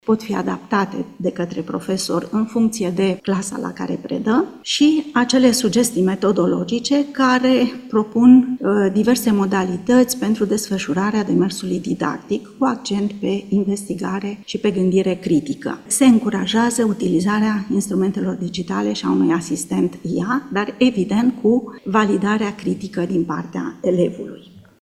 27nov-13-O-profa-despre-matematica.mp3